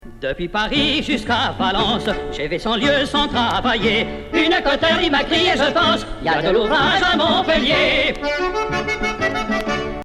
Origine : Chants compagnonniques
Indication : Chanson de chantier des tailleurs de pierre